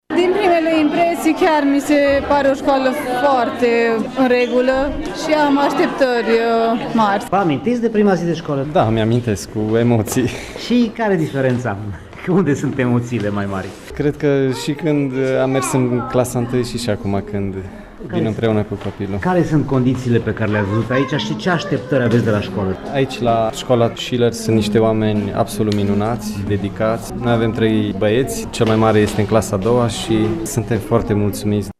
Părinții și-au amintit cu emoție de prima lor zi de școală, remarcând că azi a fost o zi foarte frumoasă. Toți au remarcat condițiile foarte bune și au așteptări mari de la școală: